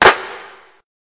Clap (2).wav